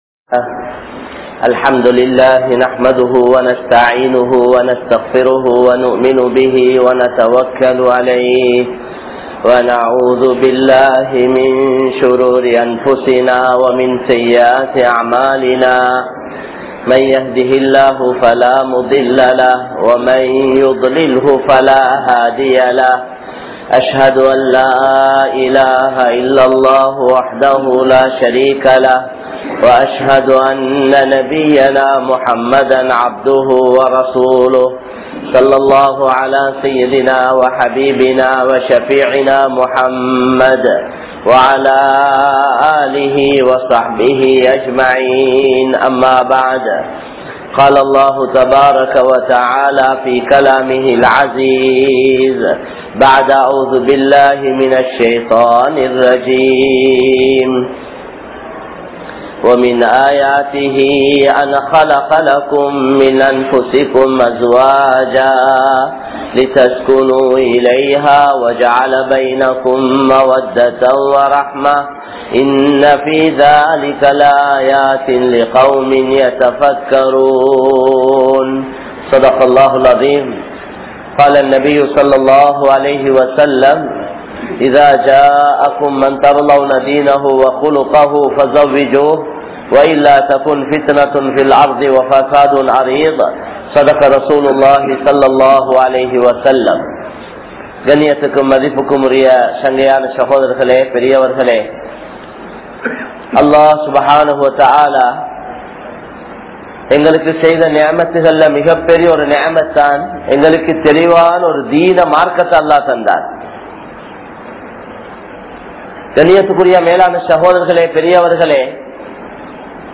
Thirumanaththin Noakkam (திருமணத்தின் நோக்கம்) | Audio Bayans | All Ceylon Muslim Youth Community | Addalaichenai